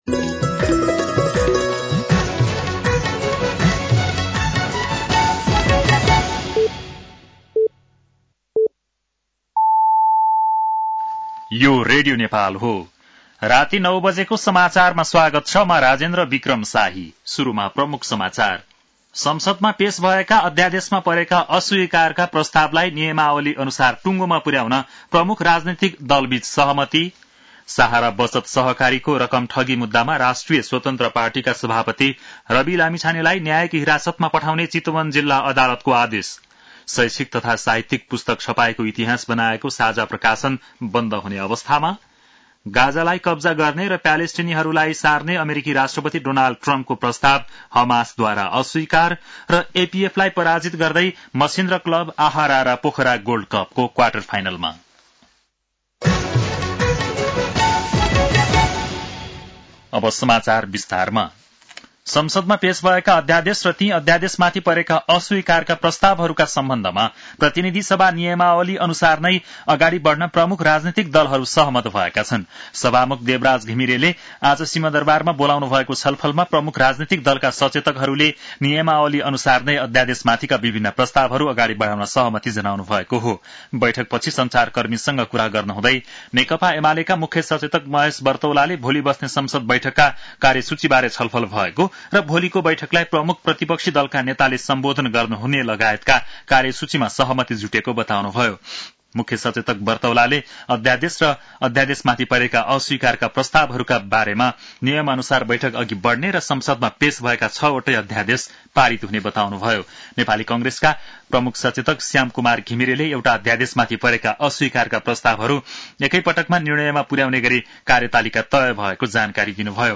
बेलुकी ९ बजेको नेपाली समाचार : २४ माघ , २०८१
9-PM-Nepali-News-10-23.mp3